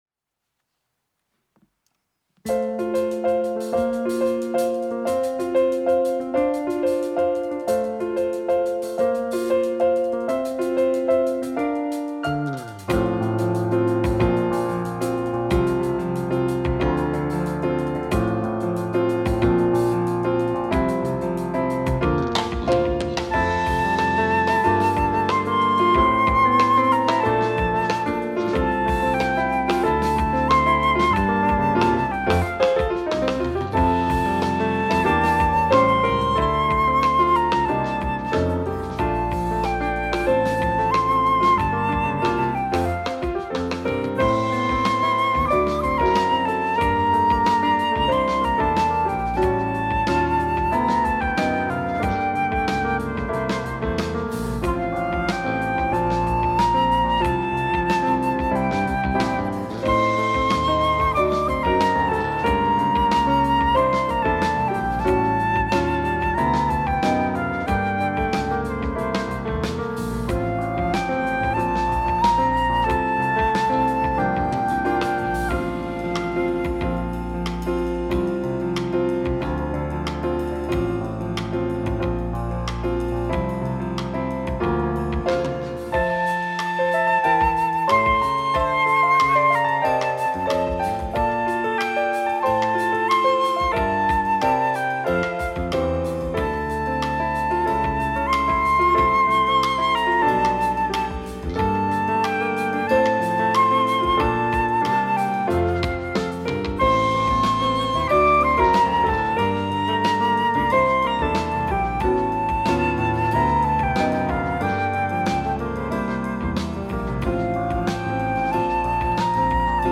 특송과 특주 - 천사들의 노래가